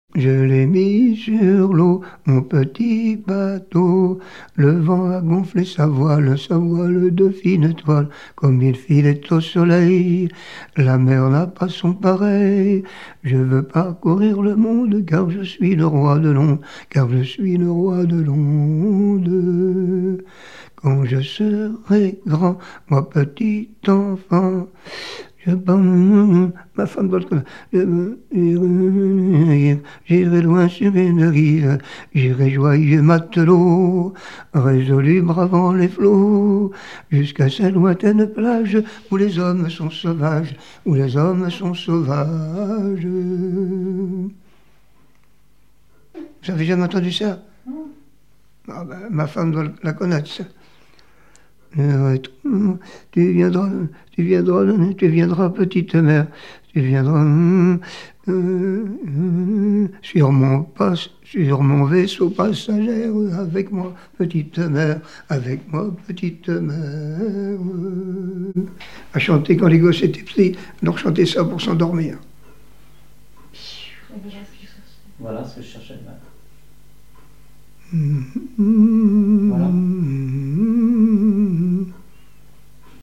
enfantine : berceuse
témoignages et chansons maritimes
Pièce musicale inédite